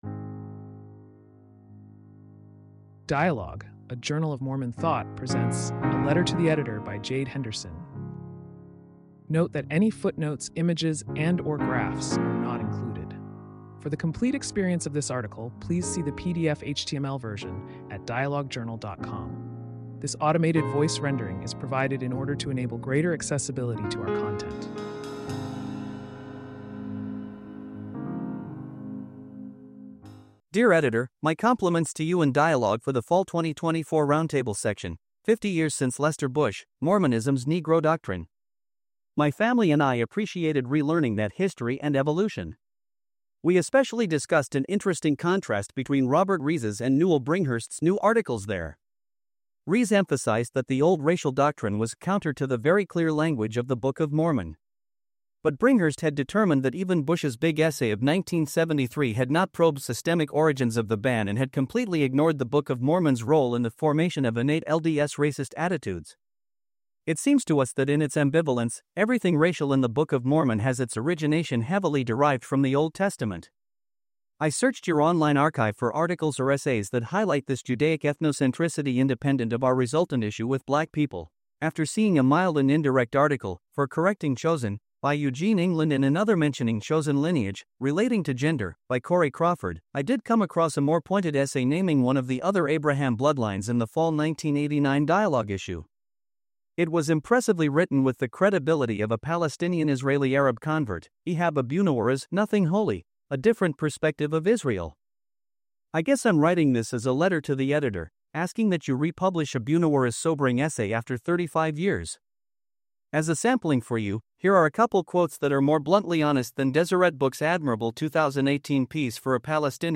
This automated voice rendering is provided in order to enable greater accessibility to our content.